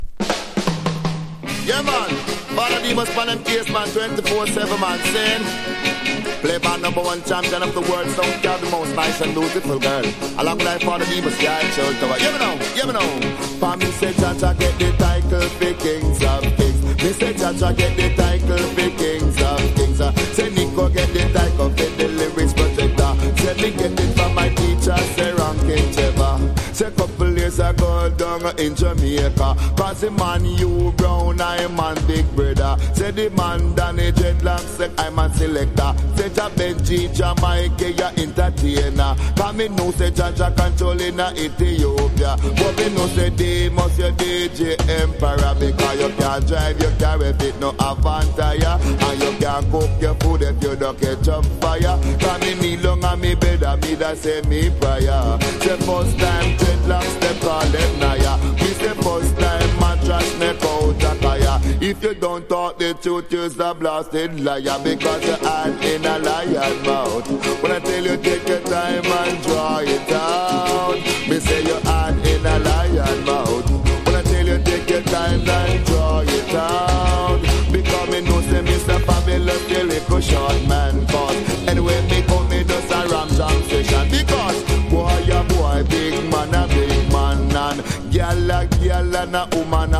DANCE HALL